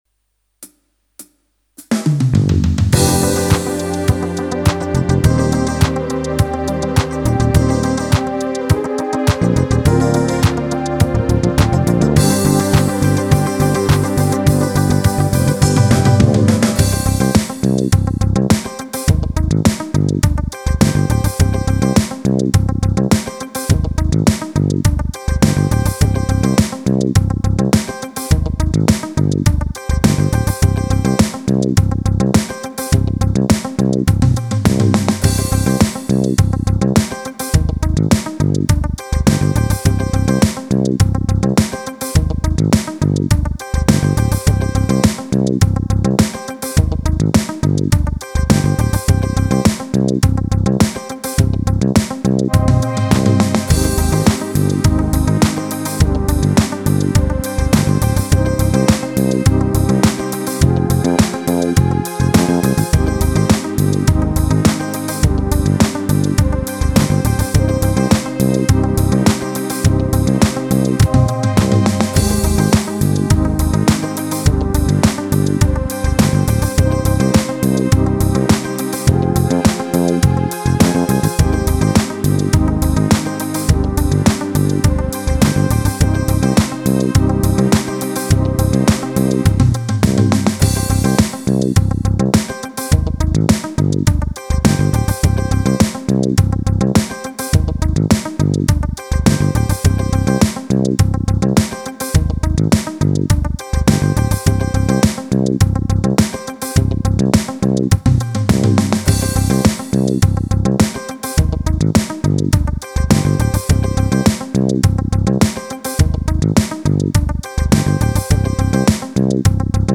8 Beat
Akkoorden progressie ritme alleen.
Roland E X 10 8 Beat 001 Cosmic Pop Ritme Alleen Mp 3